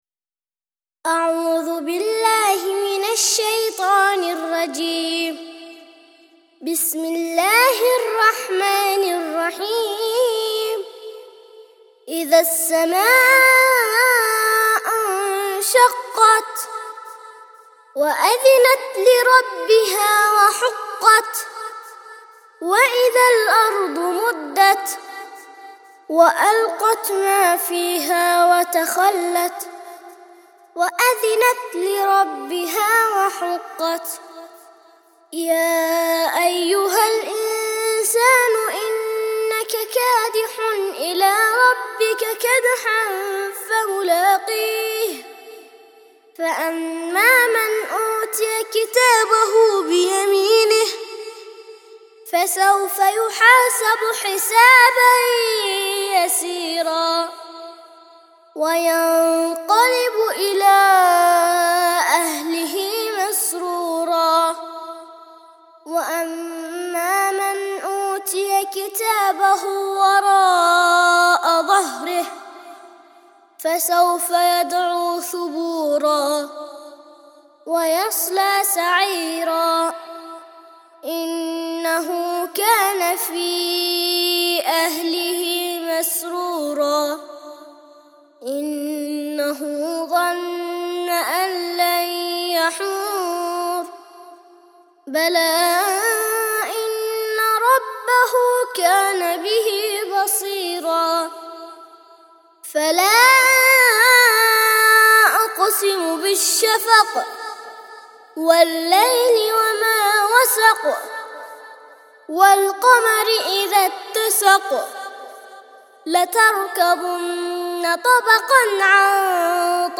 84- سورة الانشقاق - ترتيل سورة الانشقاق للأطفال لحفظ الملف في مجلد خاص اضغط بالزر الأيمن هنا ثم اختر (حفظ الهدف باسم - Save Target As) واختر المكان المناسب